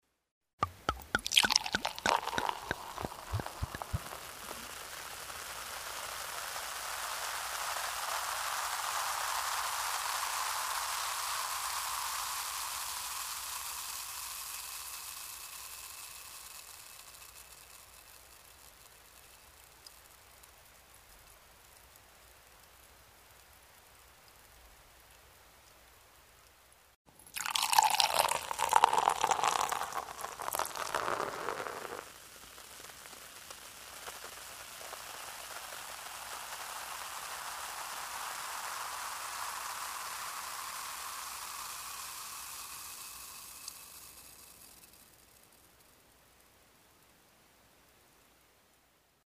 Звуки шампанского
Наливание в бокал и танцующие пузырьки: